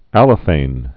(ălə-fān)